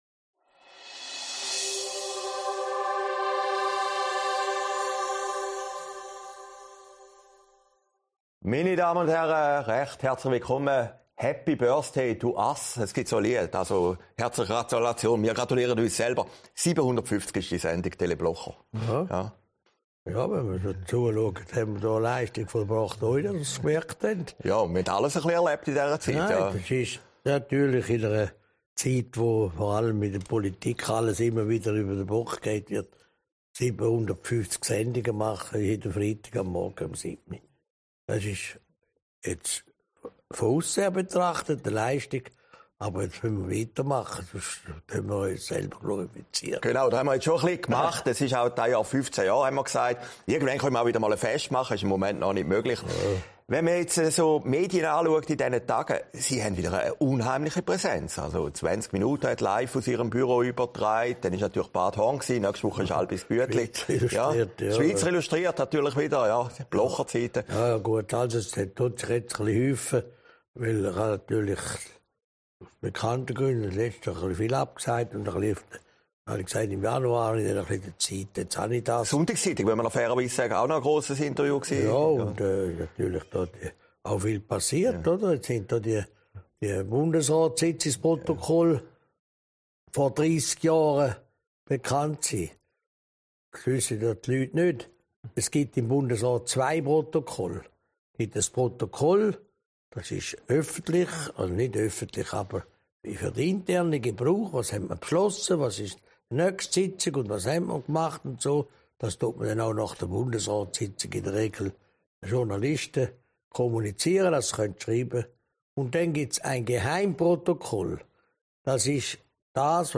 Sendung vom 14. Januar 2022, aufgezeichnet in Herrliberg